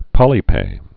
(pŏlē-pā)